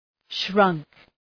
Προφορά
{ʃrʌŋk}
shrunk.mp3